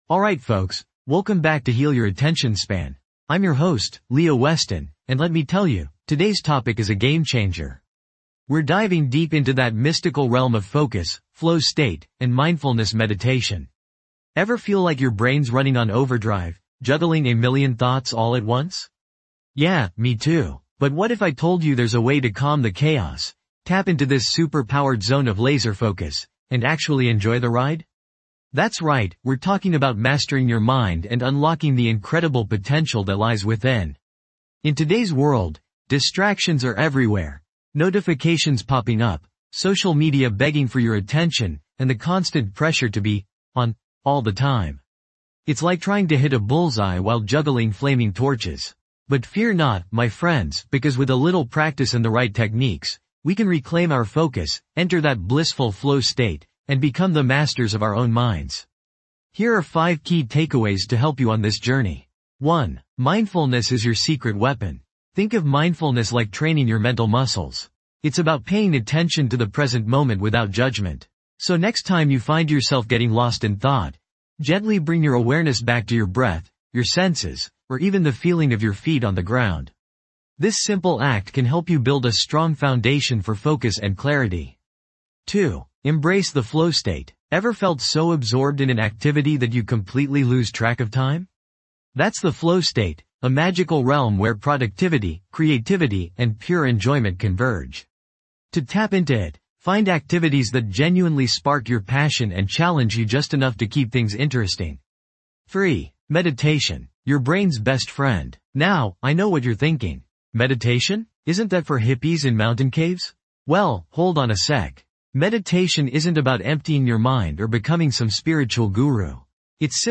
Episode Tags:. Mindfulness Meditation, Flow State, Productivity, Inner Calm, Focus, Stress Reduction
This podcast is created with the help of advanced AI to deliver thoughtful affirmations and positive messages just for you.